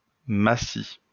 Massy (French pronunciation: [masi]